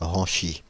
Ranchy (French pronunciation: [ʁɑ̃ʃi]